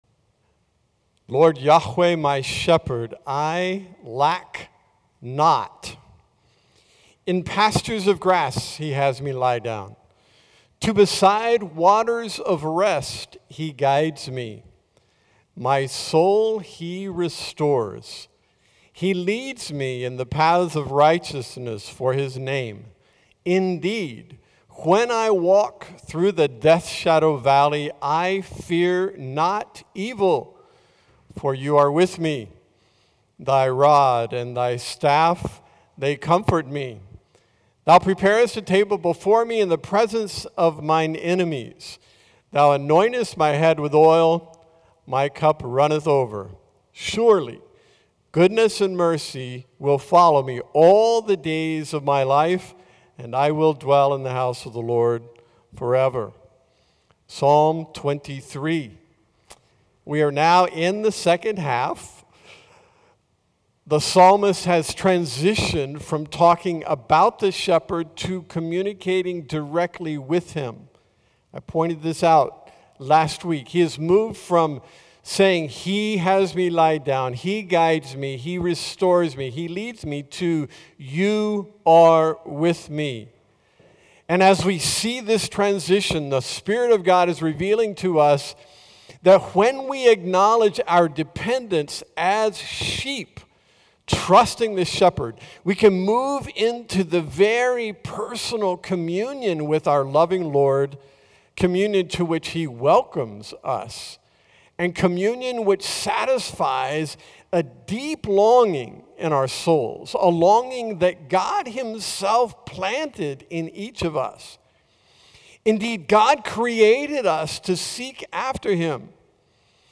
" my Shepherd " Sermon Notes Slide Show